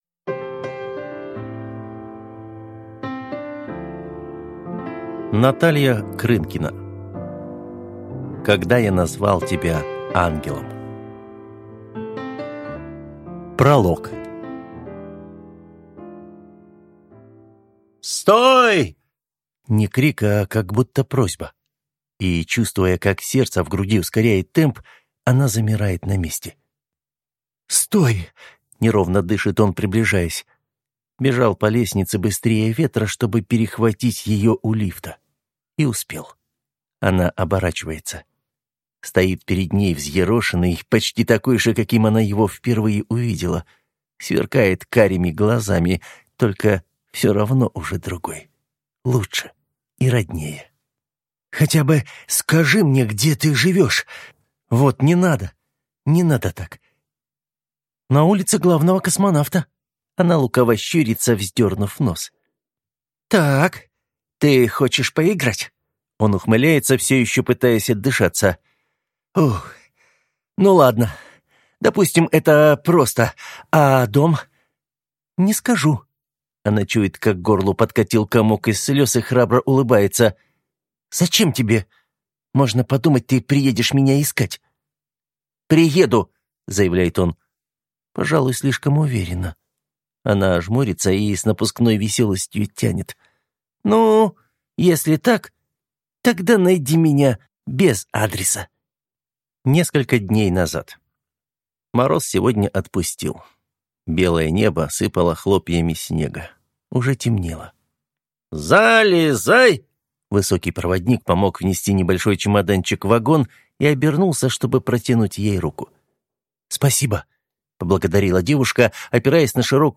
Аудиокнига Когда я назвал тебя Ангелом | Библиотека аудиокниг